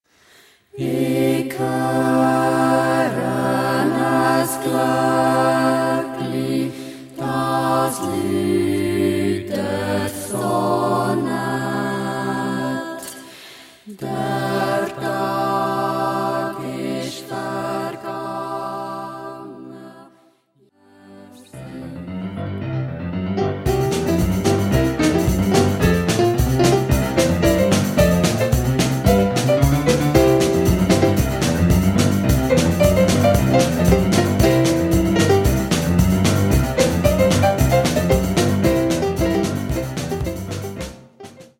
Chor, Drum Set, Klavier